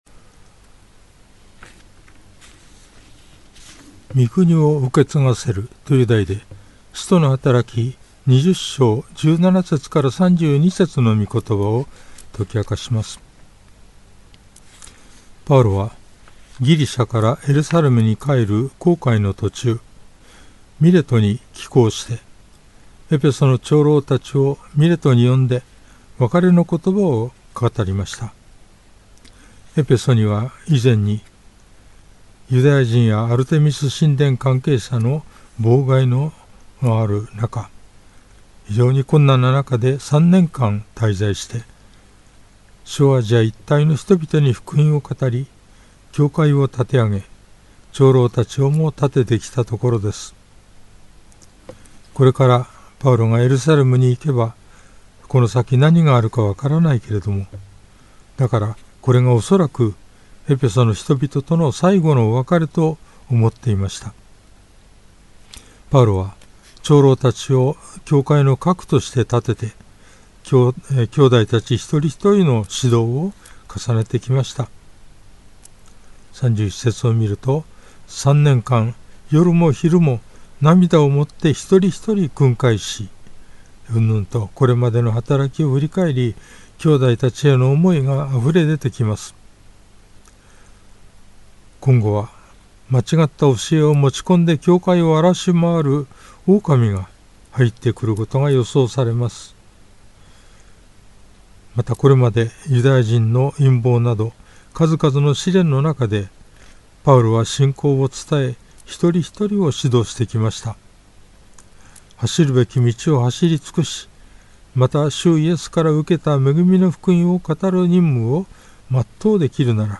主日礼拝
説教
♪ 事前録音分